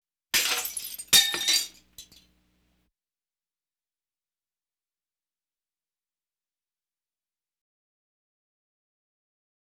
Glass Drinking Breaking Sound Effect
Download a high-quality glass drinking breaking sound effect.
glass-drinking-breaking.wav